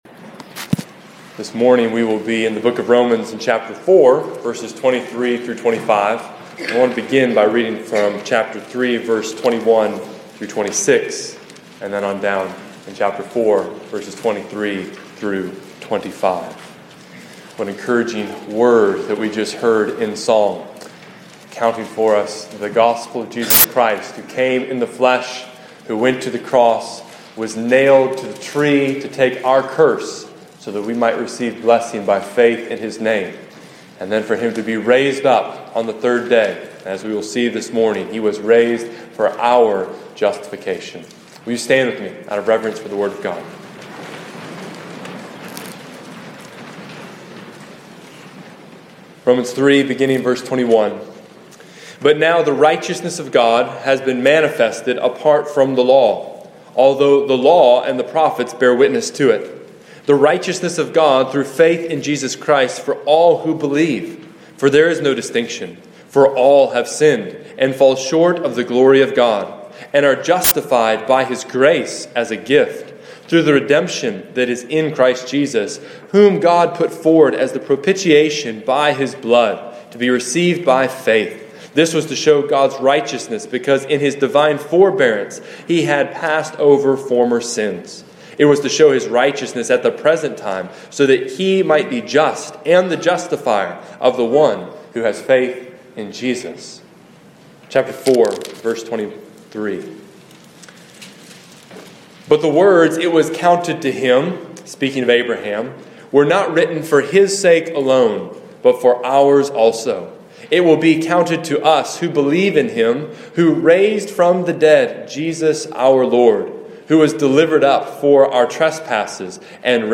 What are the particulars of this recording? On Resurrection Sunday I preached a message on Romans 4:23-25.